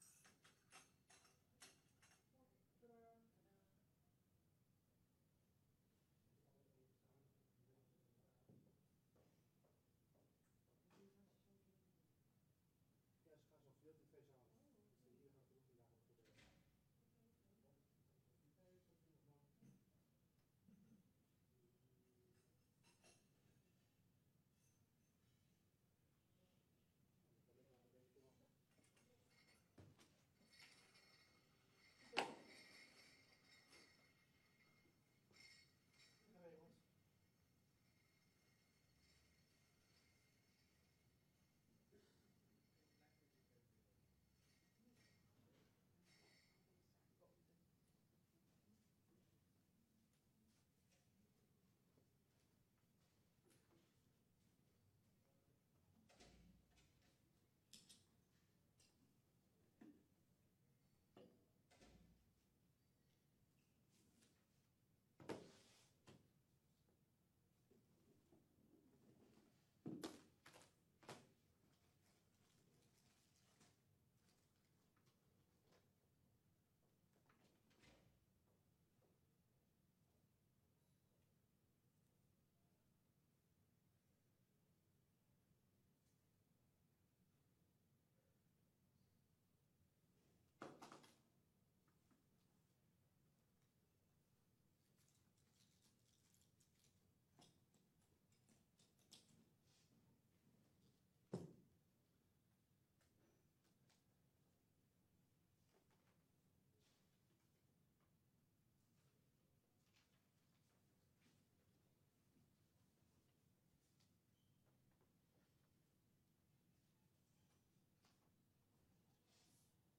De commissie krijgt de gelegenheid om na een presentatie door de Rekenkamer beeldvormende vragen te stellen